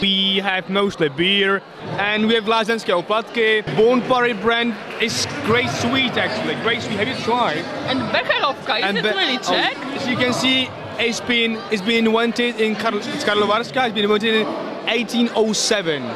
Mówią uczestnicy Forum